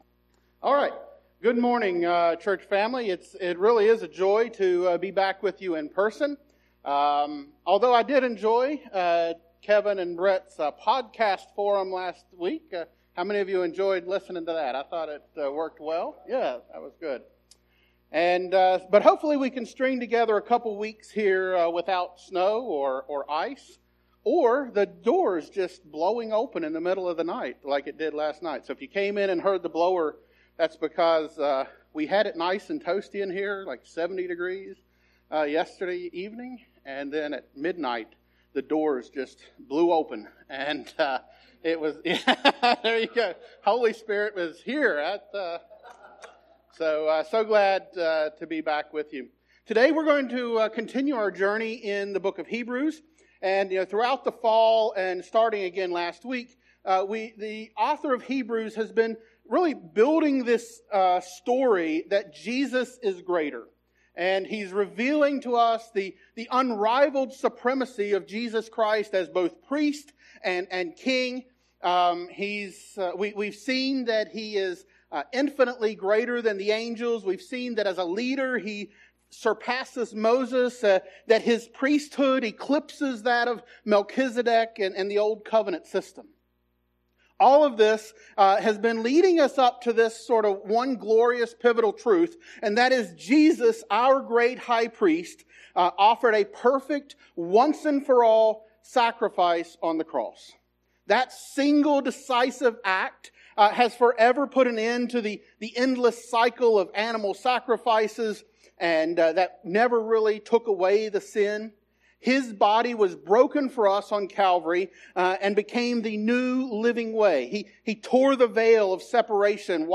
sermon-audio-trimmed.mp3